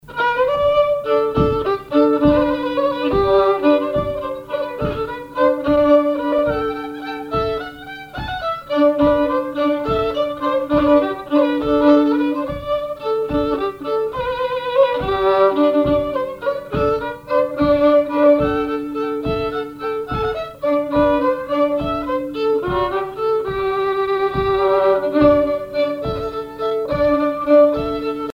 violoneux, violon
valse musette
instrumentaux au violon mélange de traditionnel et de variété
Pièce musicale inédite